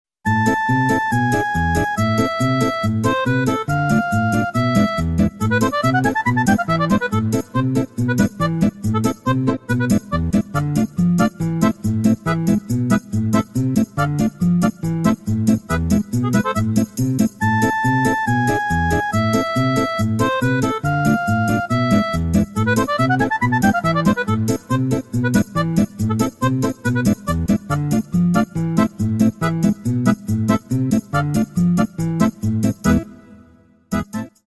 Весёлые Рингтоны